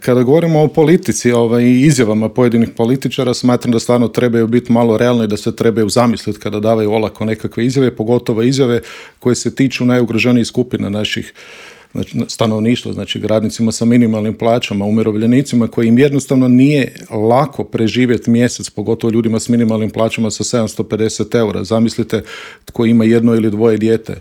Gostujući u Intervjuu Media servisa